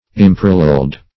\Im*par"al*leled\